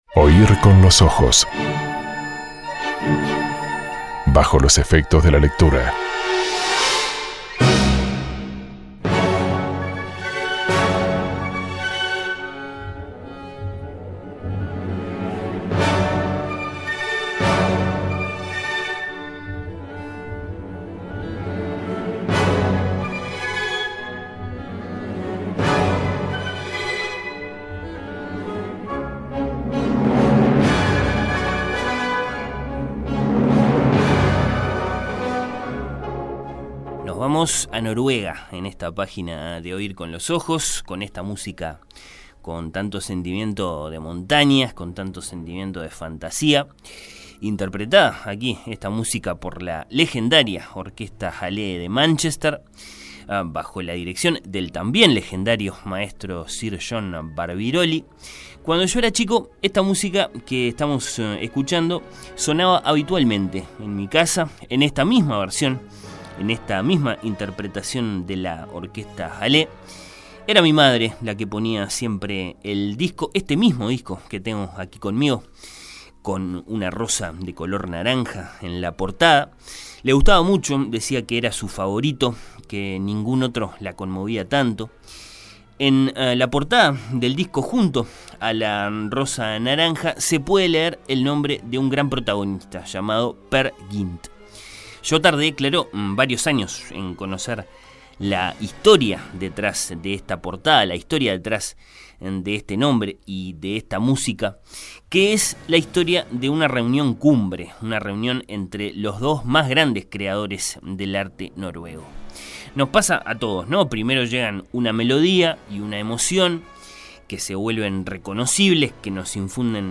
Se escucharon, de la música de escena para el Peer Gynt de Ibsen: